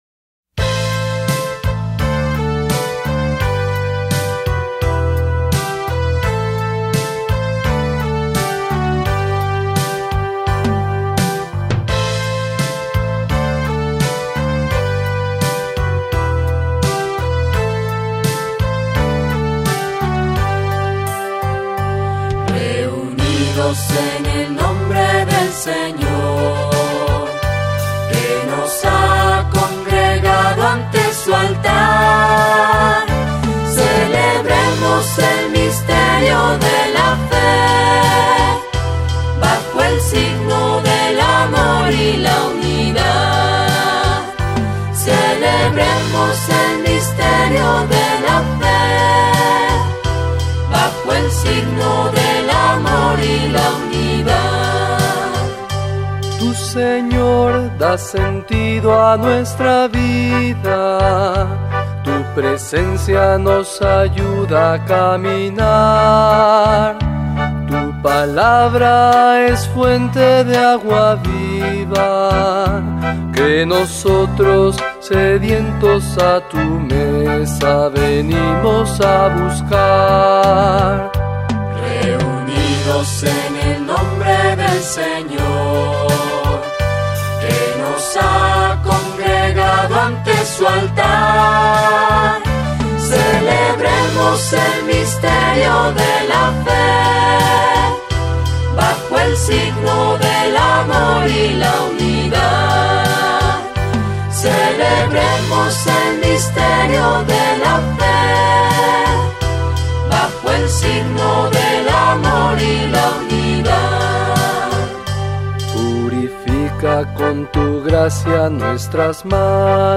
Cantos Litúrgicos